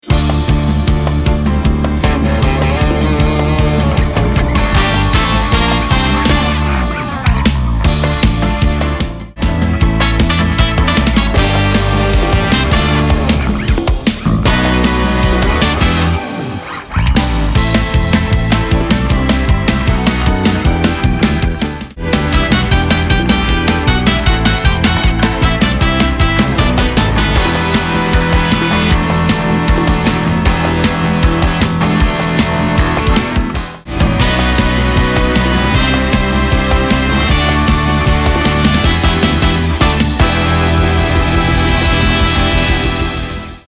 🔥 Así suena el Multitrack completo 🔽
• Compás: 4/4
• Tono: Dm – Em
• BPM: 155
• Drums
• Bass
• Percusión
• Guitarra eléctrica 1-2
• Guitarra acústica
• Hammond
• Piano
• Pad
• Synth bass
• Strings
• Trompetas